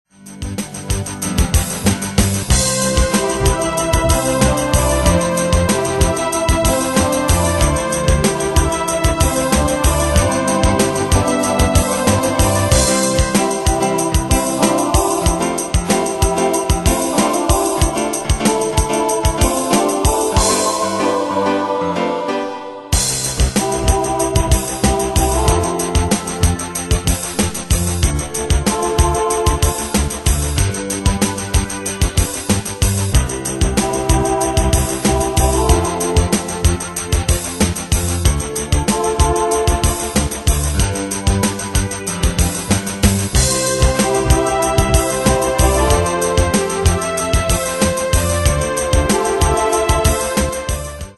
Style: PopFranco Année/Year: 1993 Tempo: 94 Durée/Time: 4.57
Danse/Dance: Rock Cat Id.
Pro Backing Tracks